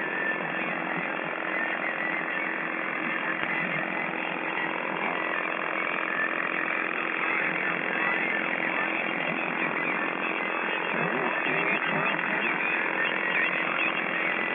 これは同じ3月9日の夕方5時ころのノイズです。
これは、ノイズというよりかは他所の国からの妨害波(OTHレーダー)かもしれません